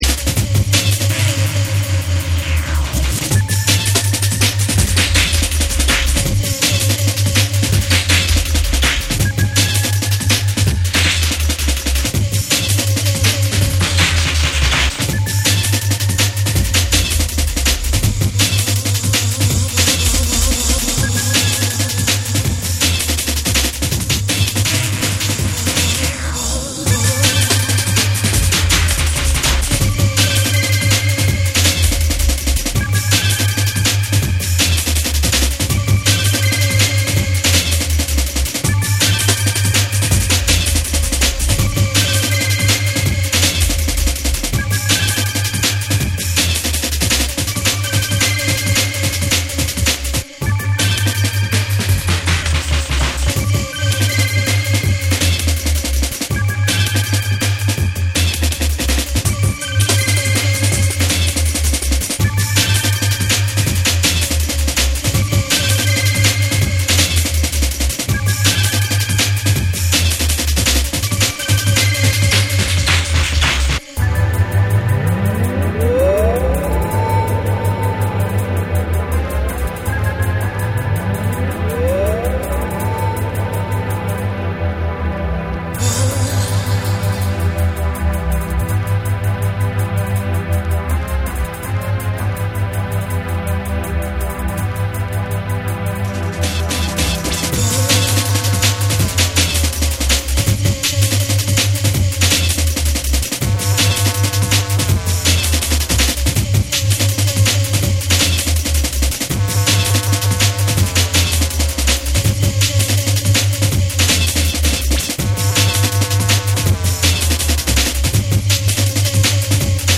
重厚なブレイクビーツ、深みのあるベースライン、浮遊感のあるシンセのメロディーが織りなすドラムンベース金字塔的アルバム！
JUNGLE & DRUM'N BASS